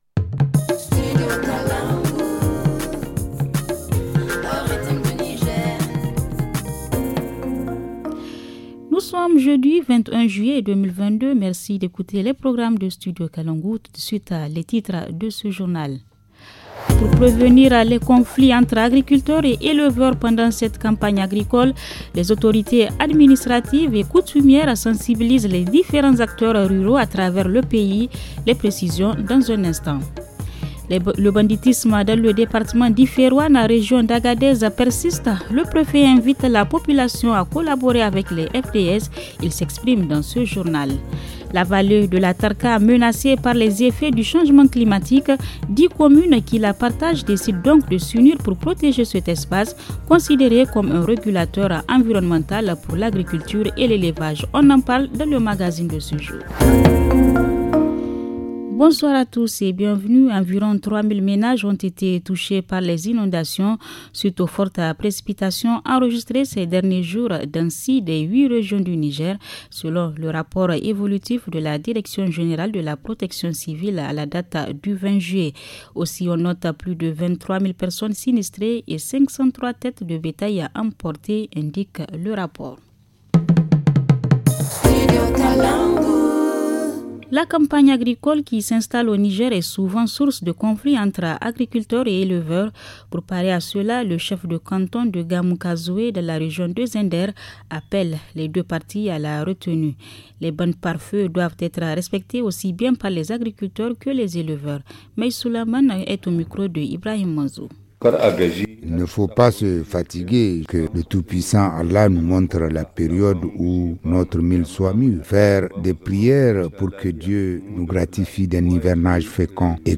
Le journal du 21 juillet 2022 - Studio Kalangou - Au rythme du Niger